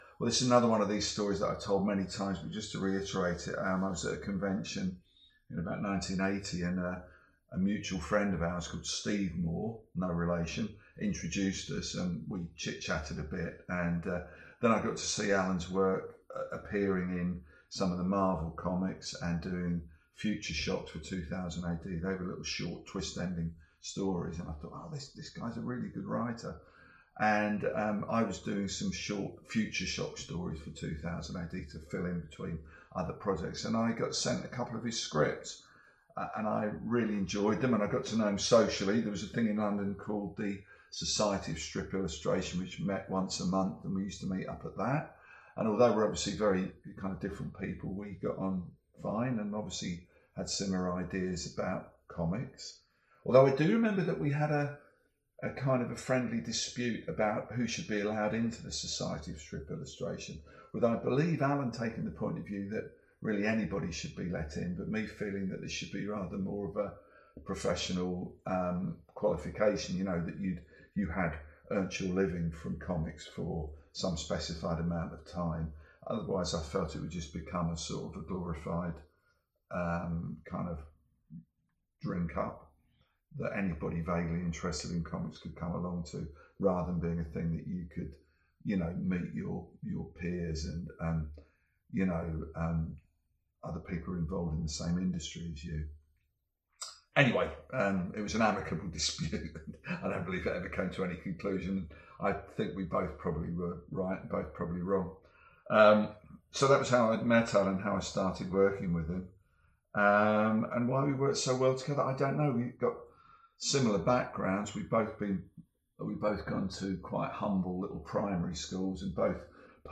Dave Gibbons interview: How did you first meet Alan Moore?